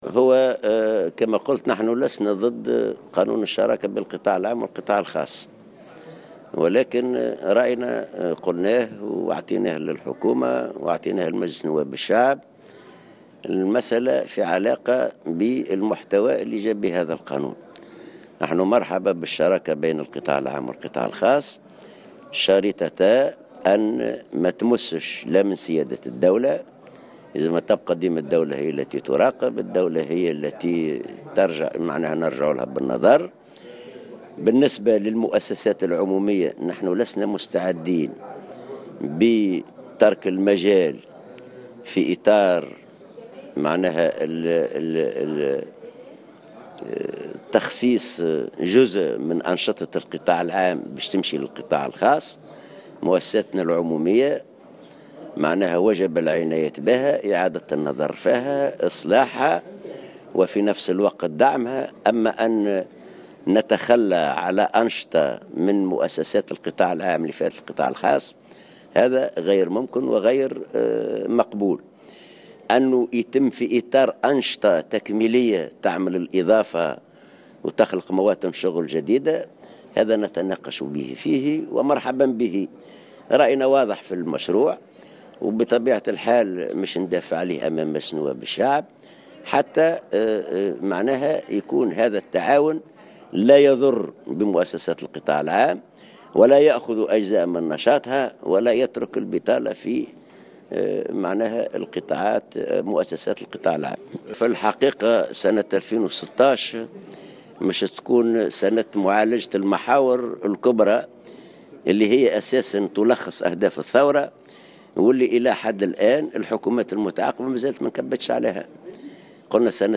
على هامش اختتام أعمال ندوة وطنية نقابية حول الشراكة بين القطاعين العام والخاص بالحمامات